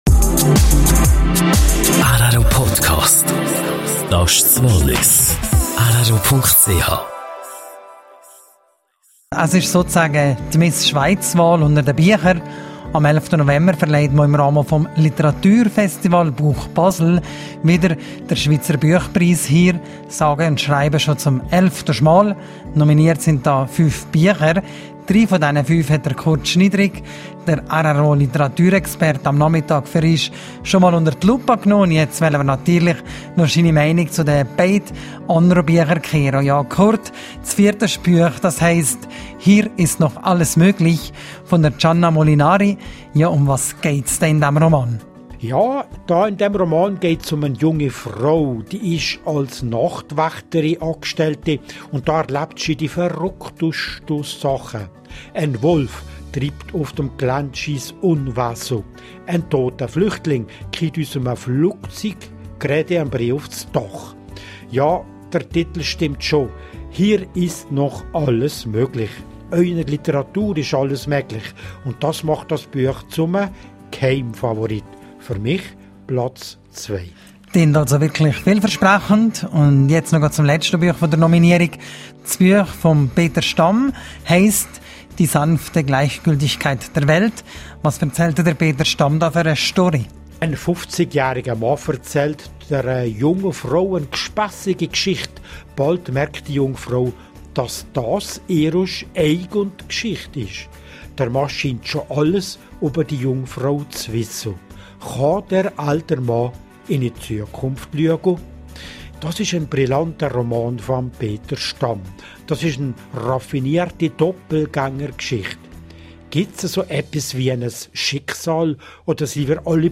Moderation